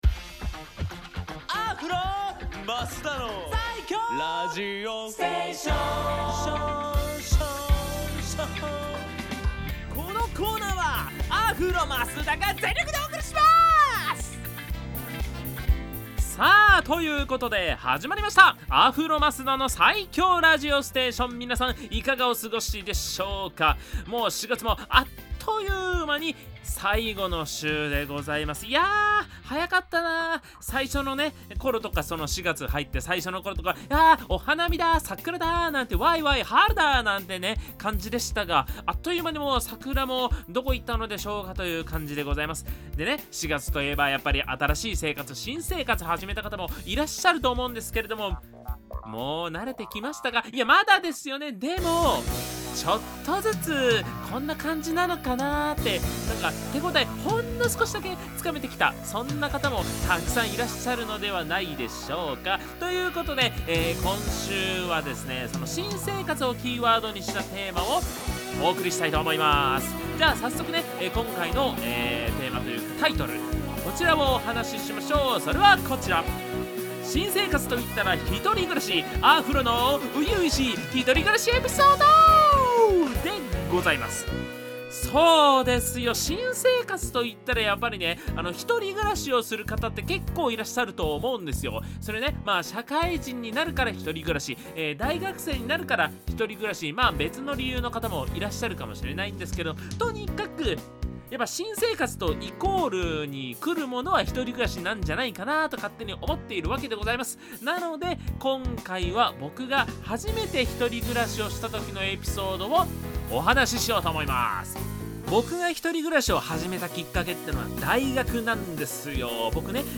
こちらのブログでは、FM83.1Mhzレディオ湘南にて放送されたラジオ番組「湘南MUSICTOWN Z」内の湘南ミュージックシーンを活性化させる新コーナー！
こちらが放送音源です♪